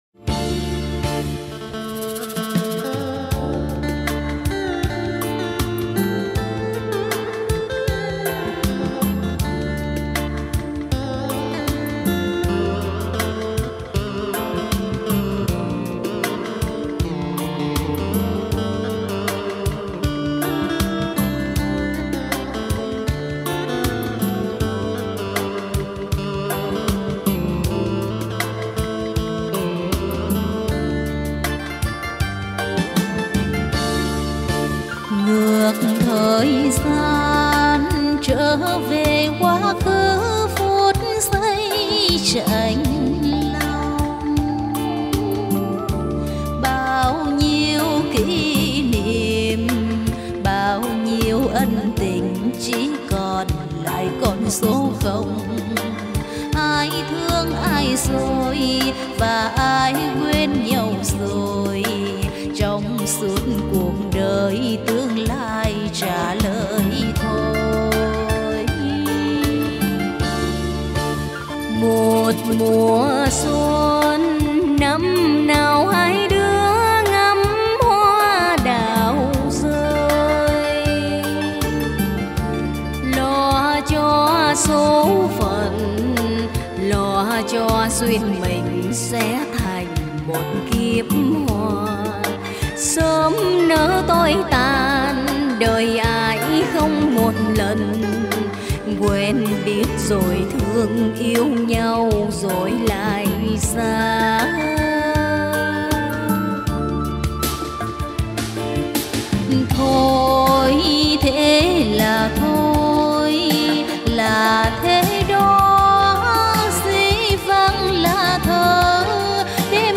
Một chiếc MV được thu âm,quay và dựng tất cả bằng điện thoại nên cả nhà nghe tạm nhé ạ !!!